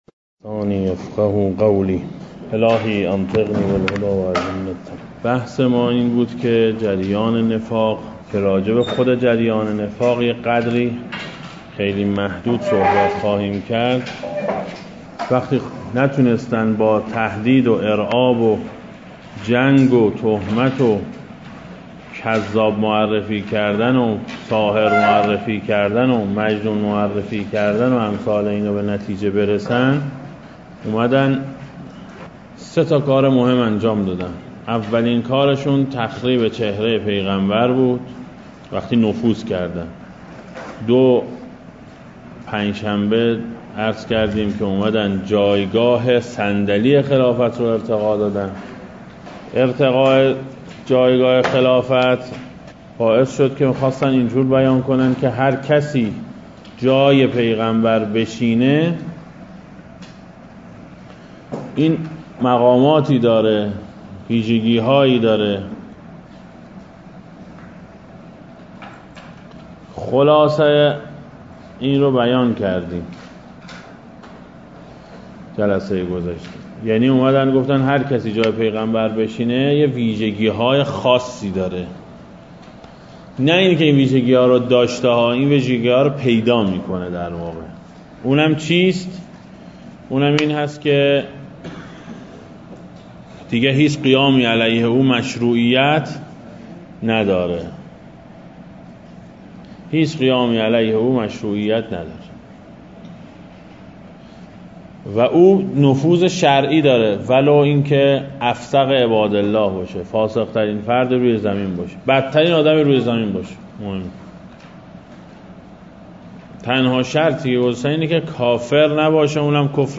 جهت دریافت صوت این کلاس، اینجا کلیک نمایید.